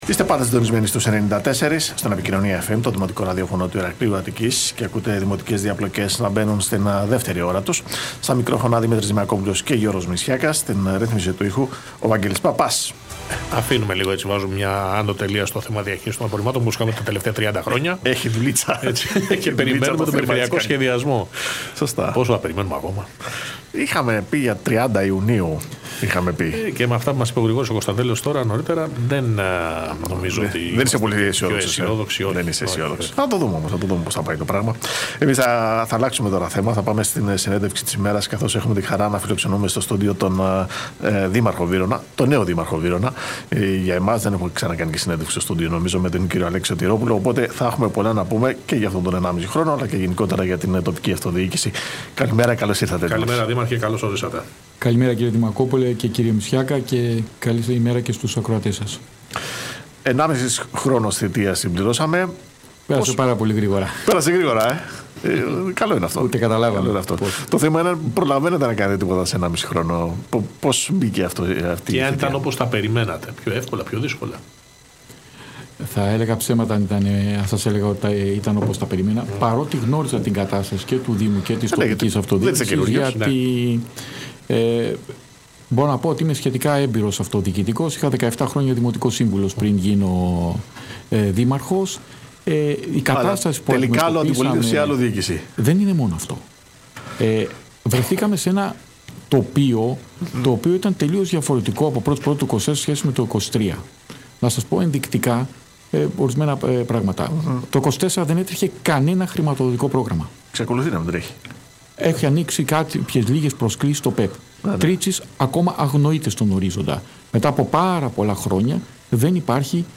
Συνέντευξη του Δημάρχου Βύρωνα Αλέξη Σωτηρόπουλου στον Ραδιοφωνικό Σταθμό «Επικοινωνία FM» 94.0
Ο Δήμαρχος Βύρωνα Αλέξης Σωτηρόπουλος παραχώρησε συνέντευξη στο ραδιοφωνικό σταθμό «Επικοινωνία FM»